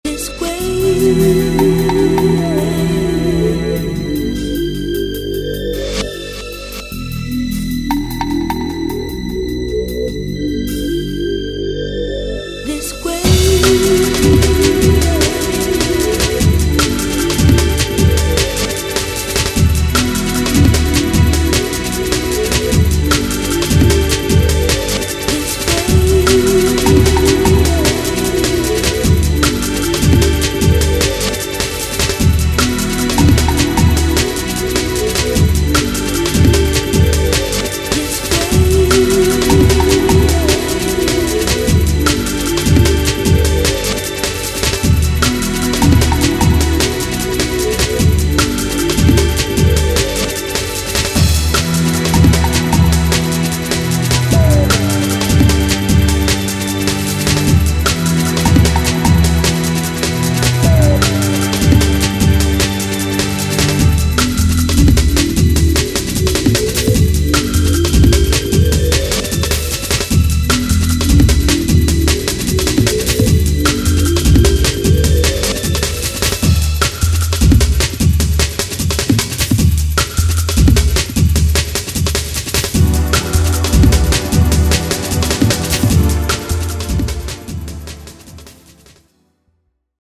copy per customer *** Classic UK Hardcore from 91
All tracks remastered